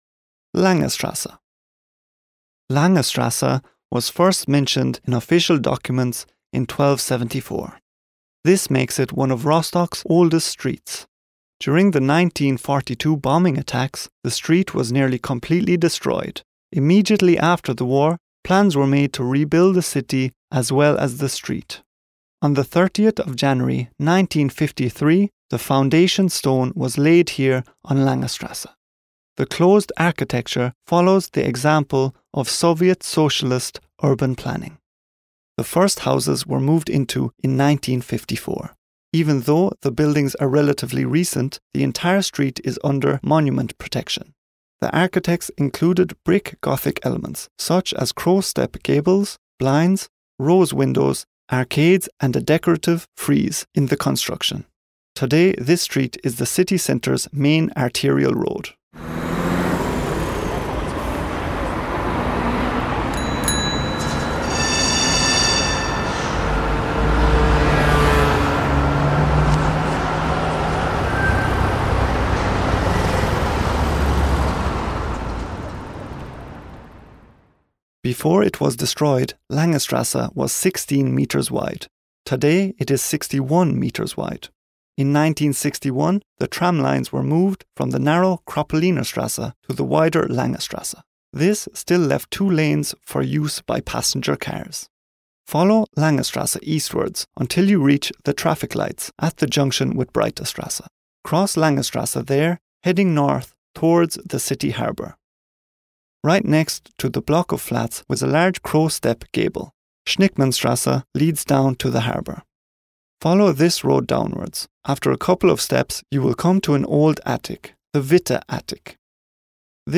Audioguide